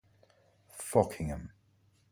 Folkingham (/ˈfɒkɪŋəm/
FOK-ing-əm) is a village and civil parish[2] in the South Kesteven district of Lincolnshire, England.